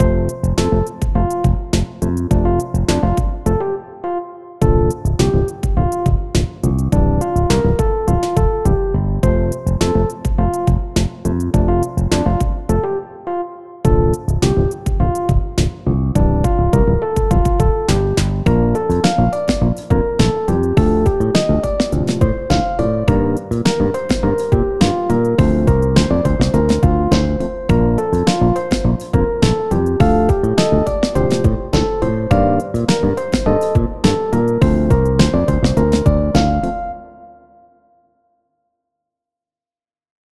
Art Type: Music
chill